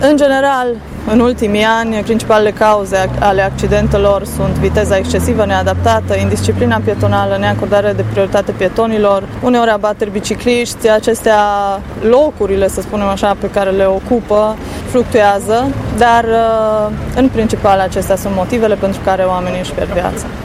Inspectoratul de Poliţie Judeţean Mureş a organizat aseară o manifestare dedicată memoriei victimelor decedate în accidente rutiere.